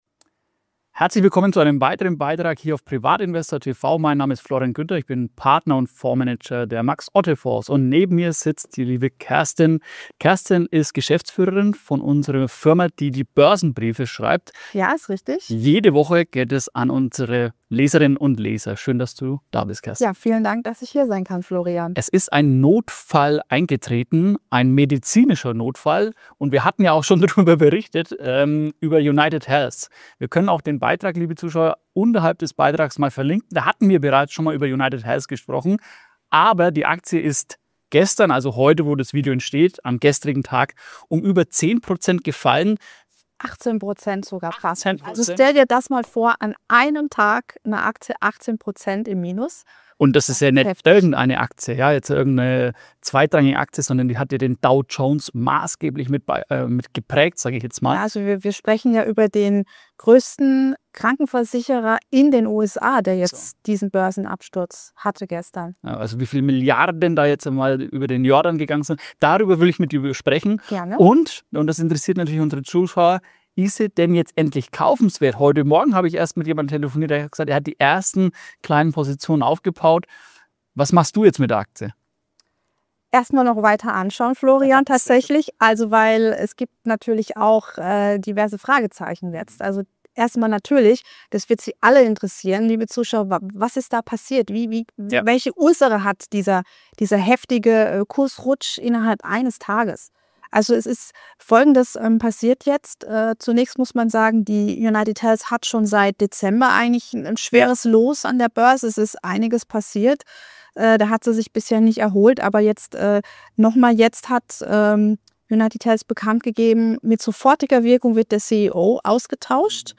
Aktiengespräch